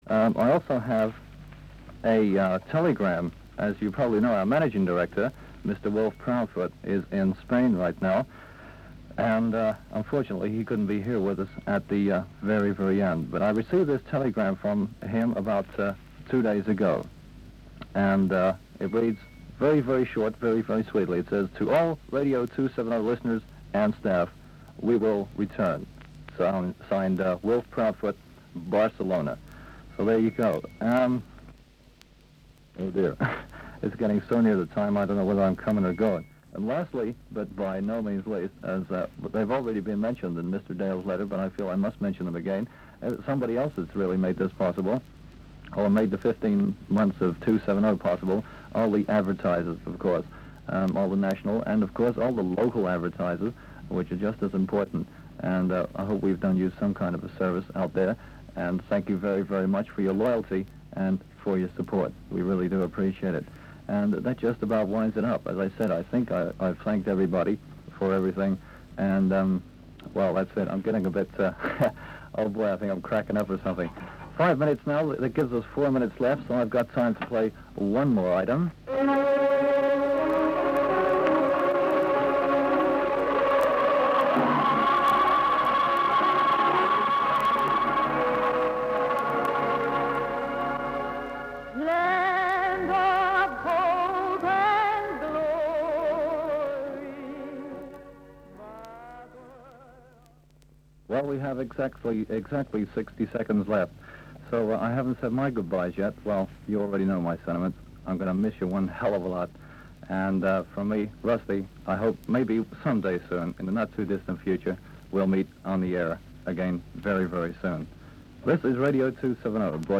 Radio London closedown 14 August 1967.mp3
Radio London closing